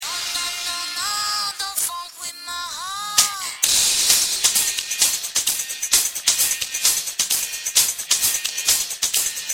Stop smooching and blasting your car stereo at the same time mutherfoggers!